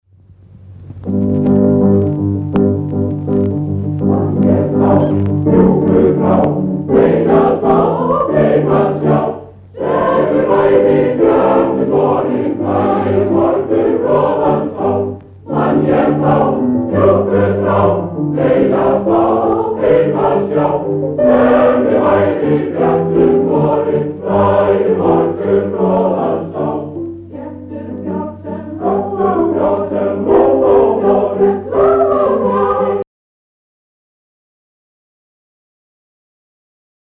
Blandaður kór
Kantötukór Akureyrar syngur í tóndæminu. Höfundur stjórnar. Tekið upp á æfingu.